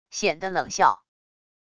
险的冷笑wav音频